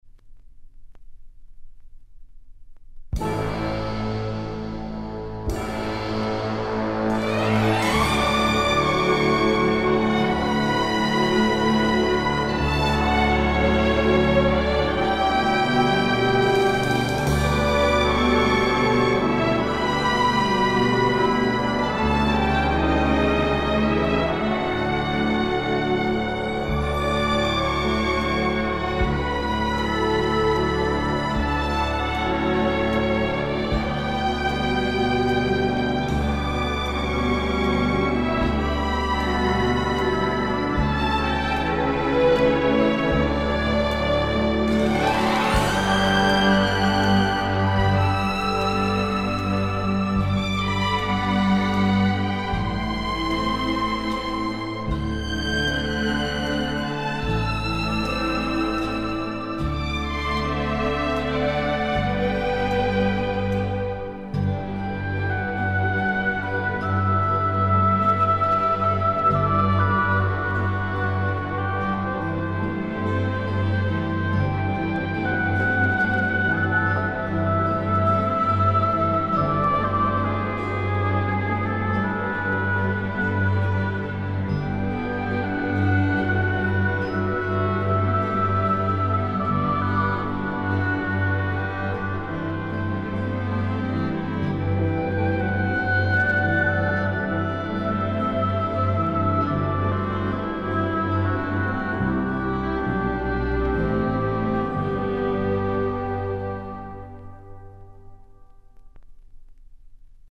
Stereo
以弦乐为主，长笛和双簧管交相辉映，应主要是营造气氛、烘托剧情。
试听：   电视剧音乐主题